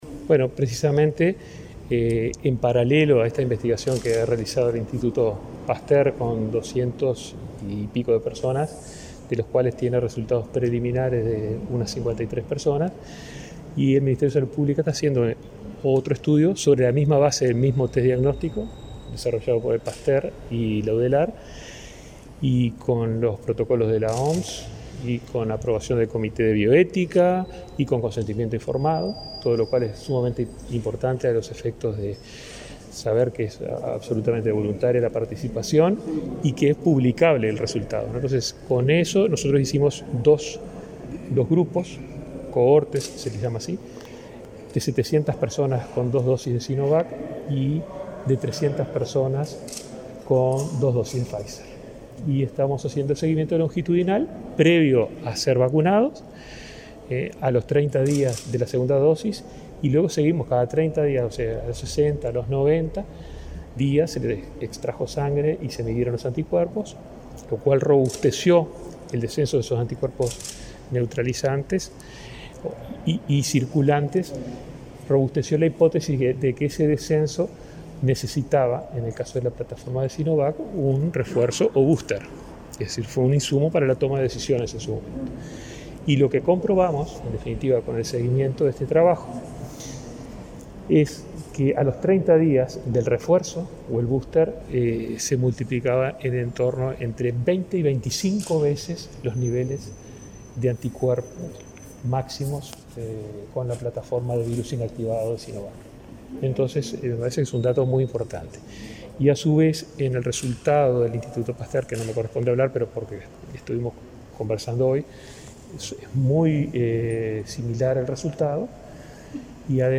Declaraciones a la prensa del ministro de Salud Pública, Daniel Salinas
Declaraciones a la prensa del ministro de Salud Pública, Daniel Salinas 24/09/2021 Compartir Facebook X Copiar enlace WhatsApp LinkedIn Tras la presentación del estudio sobre la efectividad de las vacunas contra la COVID-19, este 24 de setiembre en la Universidad de la República, el ministro Salinas efectuó declaraciones a la prensa.